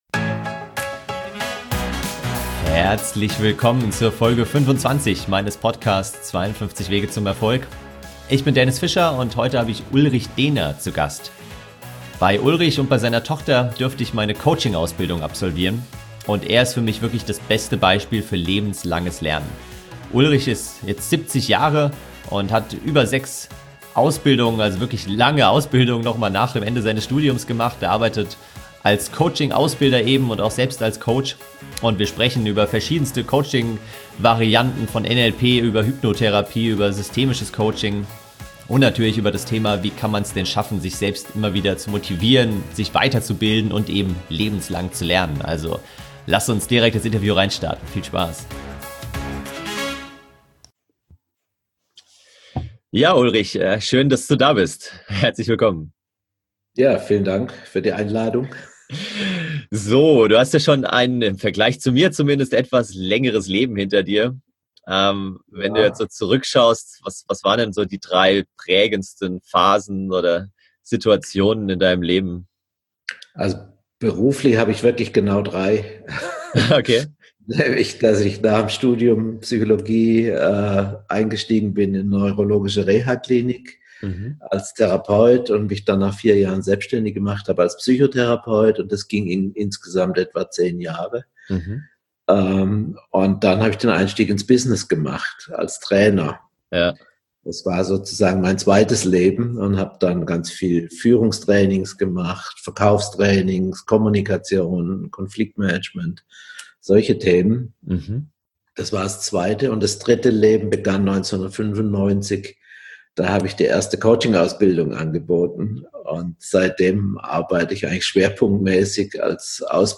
Lebenslanges Lernen - Inspirierendes Interview